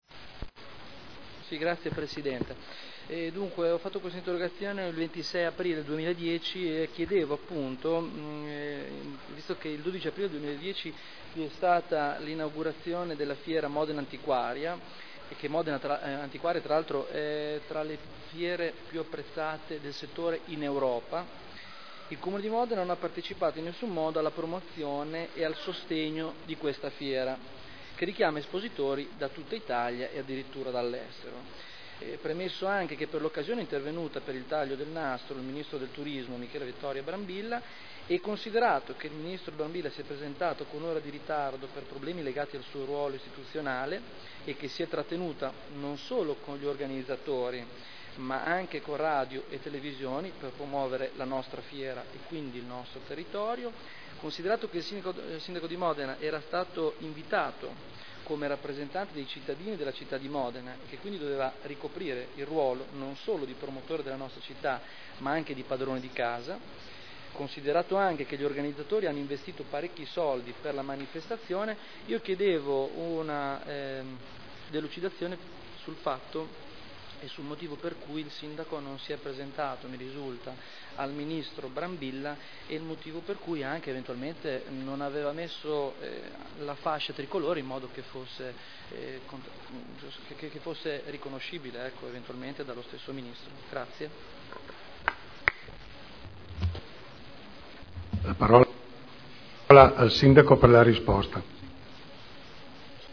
Interrogazione del consigliere Barberini (Lega Nord) avente per oggetto: “Inaugurazione unica “Modenantiquaria” del 12 febbraio 2010”